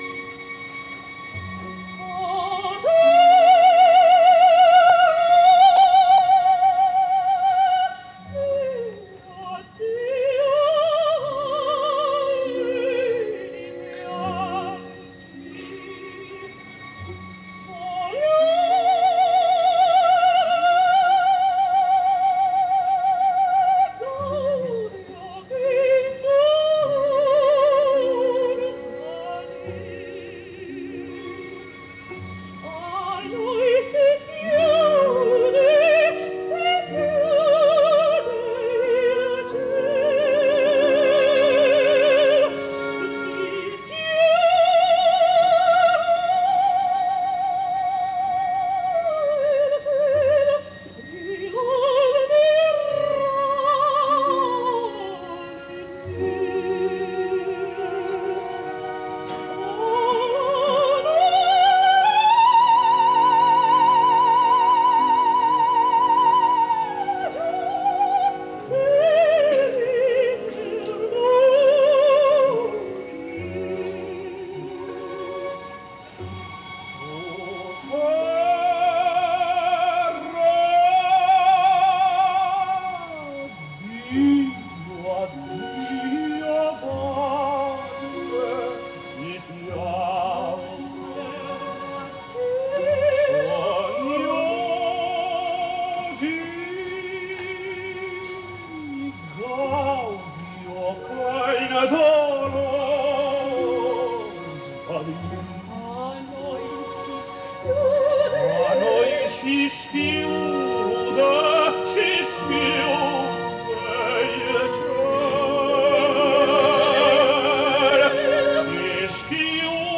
Заключительный дуэт
трансляционная запись